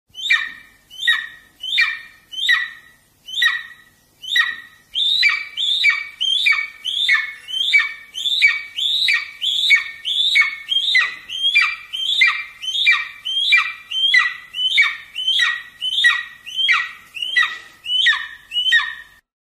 Звуки орла
На этой странице собраны разнообразные звуки орла: от пронзительных криков до низкого клекота.